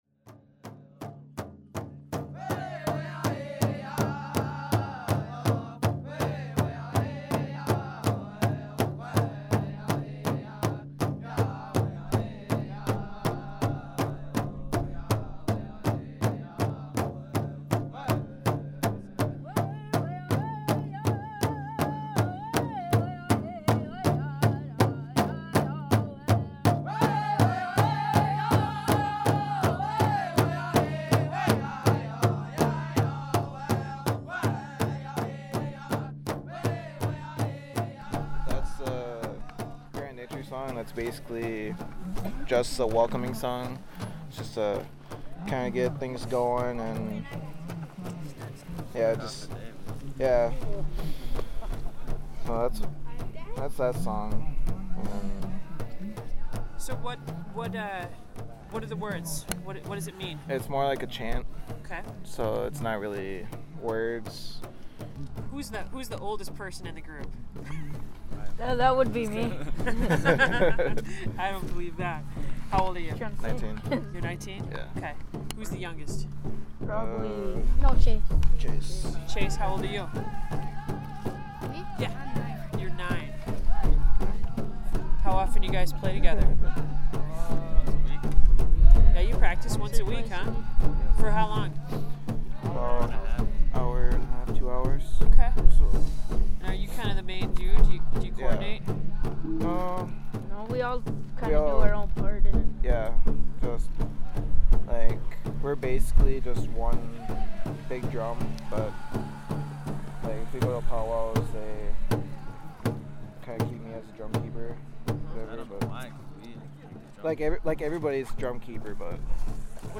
The Stone Bridge Singers Drum is a group made up of 11 young men from Grand Portage. They kicked off Thursday’s “Sled Dogs To St. Paul Rally” in Grand Marais’ Harbor Park.
Live Music Archive